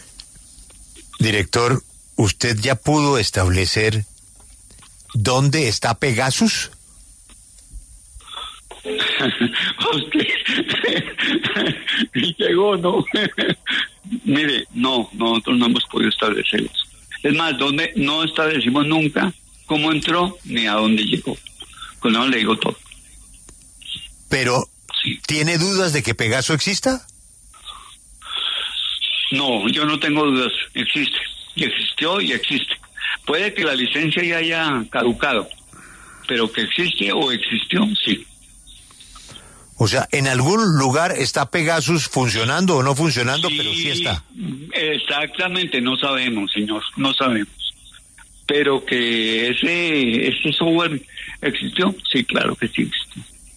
En diálogo con Julio Sánchez Cristo para La W, el director de la Dirección Nacional de Inteligencia (DNI), Jorge Lemus, se pronunció acerca de la polémica en torno al software espía Pegasus desarrollado por la empresa israelí NSO Group.